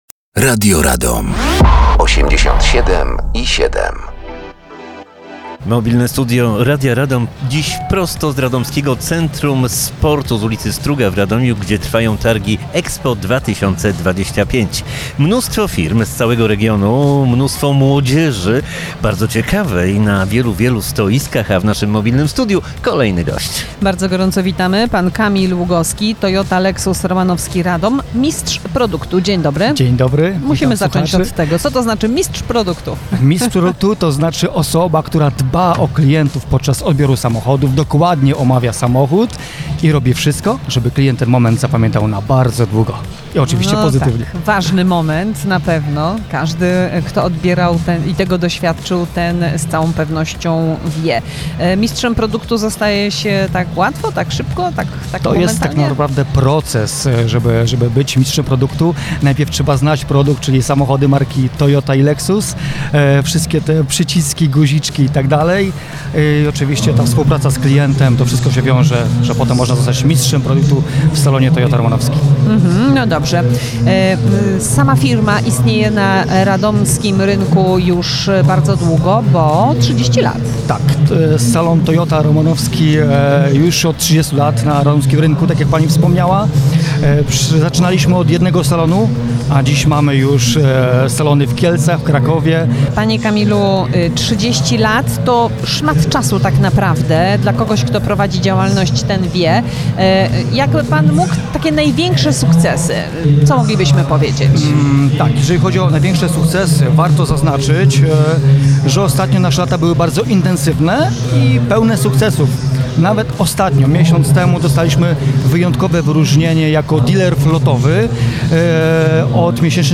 Trwają Targi EXPO 2025.